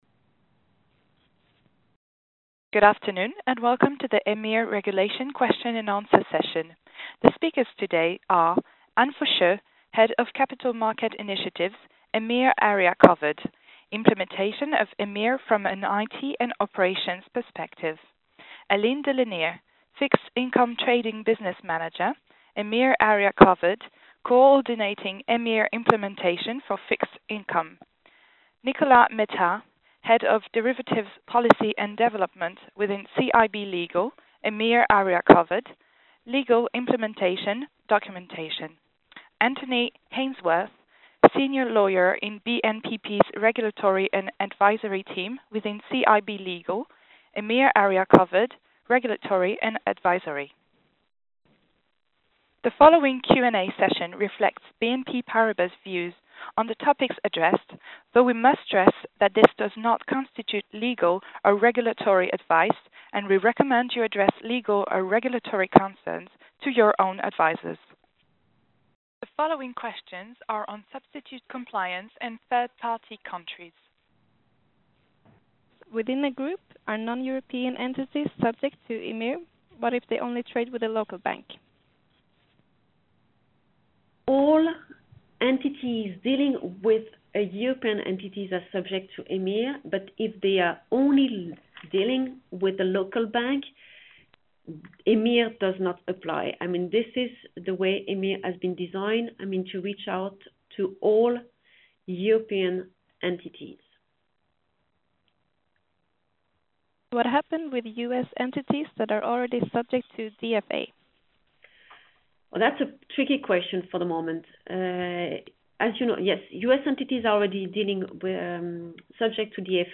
Listen to Q&A session EMIR Trade Reporting for European Corporates, 30/01/2014:
EIR-Regulations-For-European-Corporates-Call.mp3